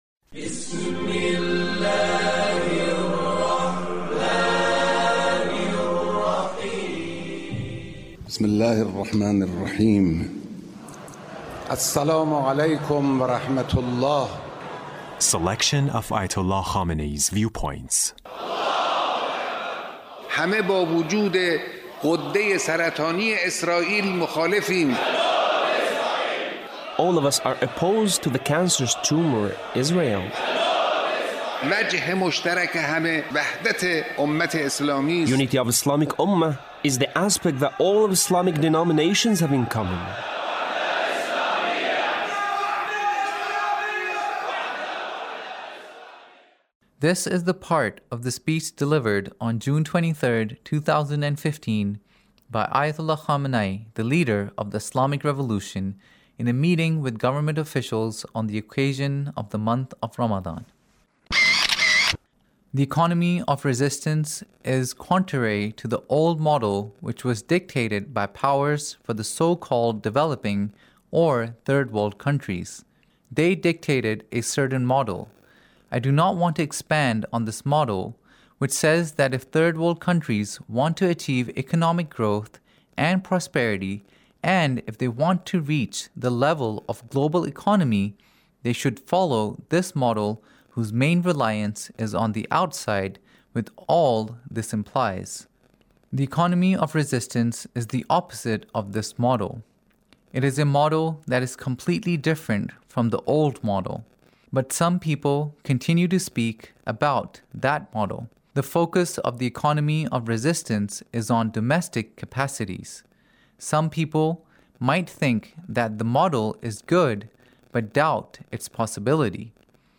Leader's Speech On The Month of Ramadhan in a Meeting with the Government Officials